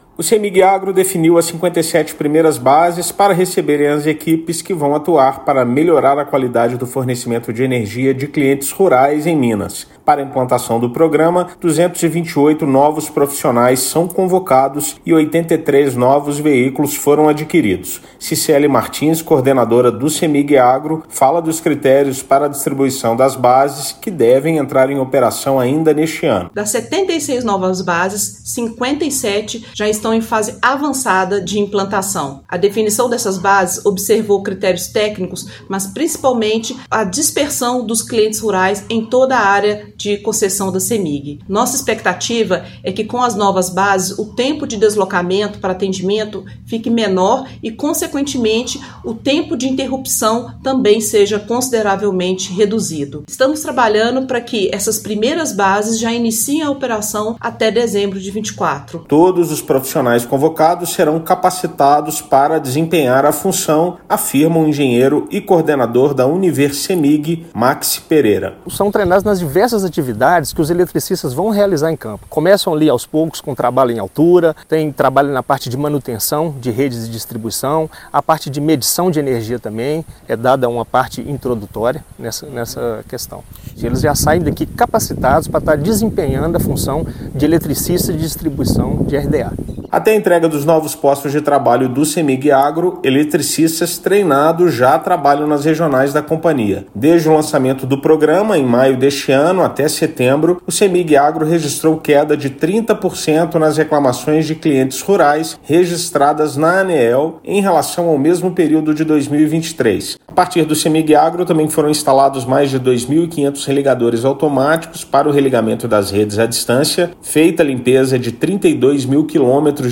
Companhia estatal divulga 57 locais que vão receber equipes para agilizar o atendimento a clientes rurais; 228 novos profissionais já estão sendo treinados. Ouça matéria de rádio.